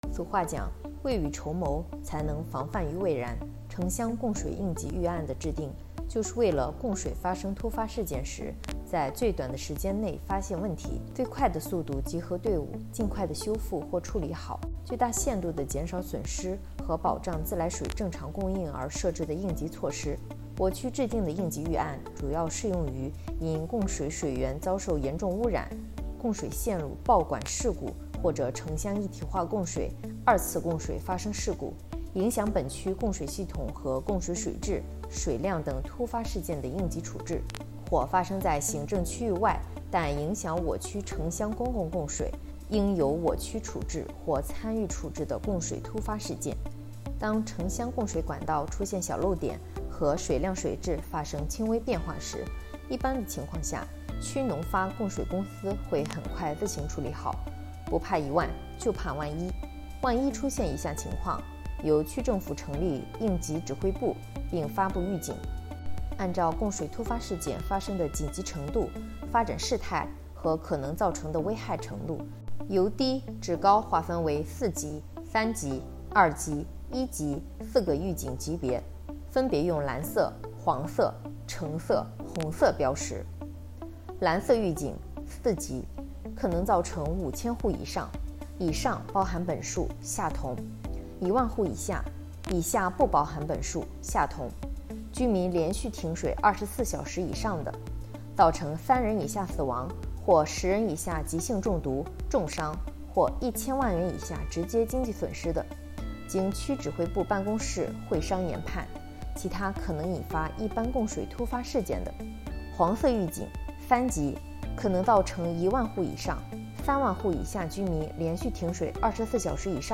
首页 > 政务公开 > 有声朗读 > 内容详情